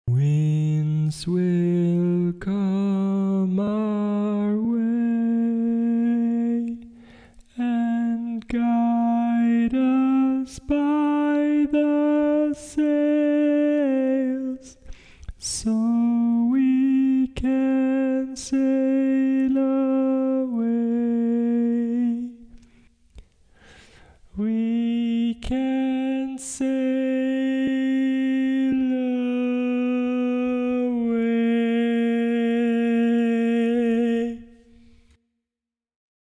Key written in: E♭ Major
Type: Barbershop
Comments: Original tag, ballad-style barbershop
Each recording below is single part only.